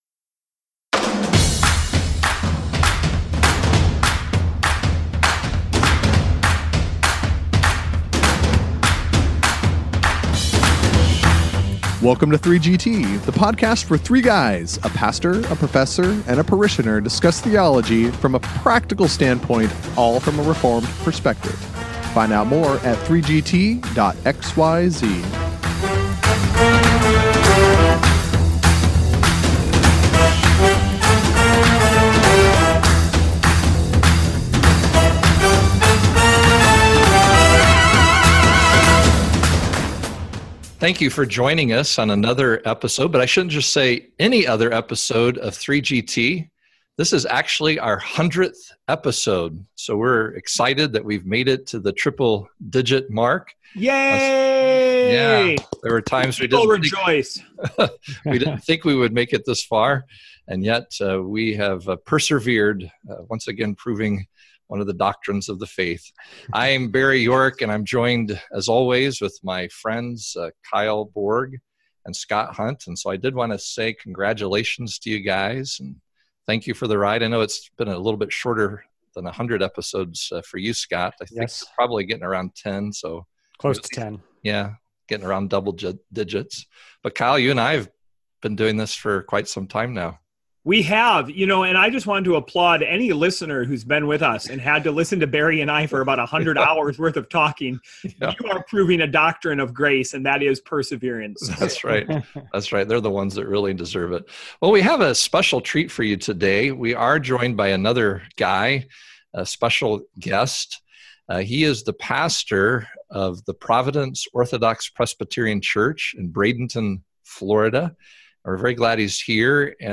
It would a tear-inducing episode…except these twins cannot stop razzing each other.